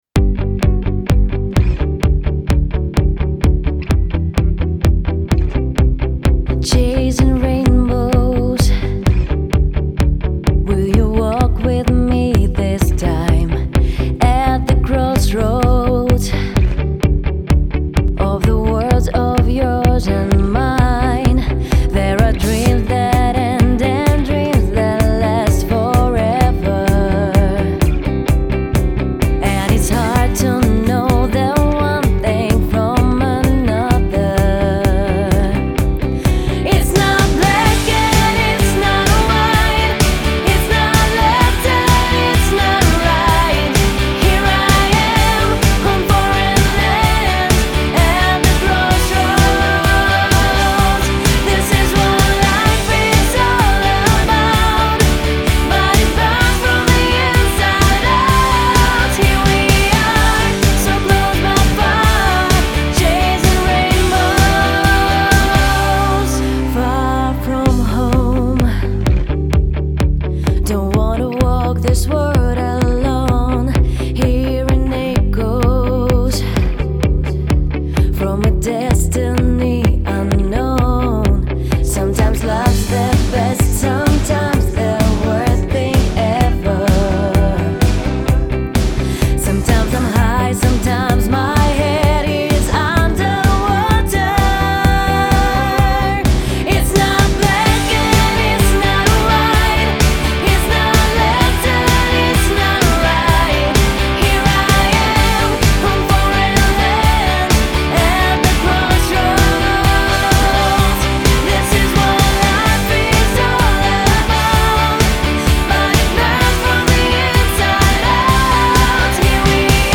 with the vocal timbre similar to Kelly Clarkson